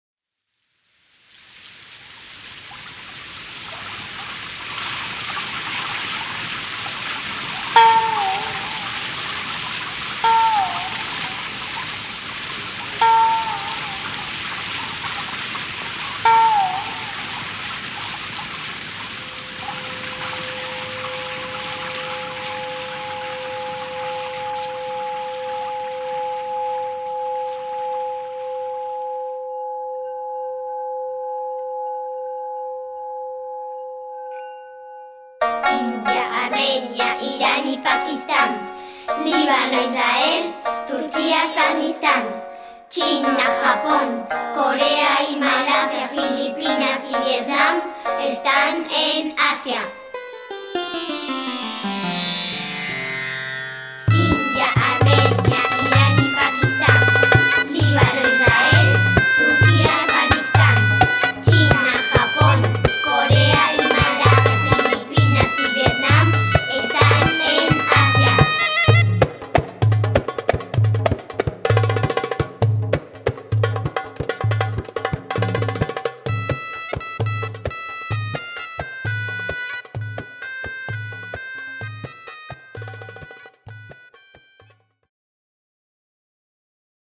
inspirada en la música oriental